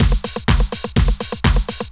Event Beat 4_125.wav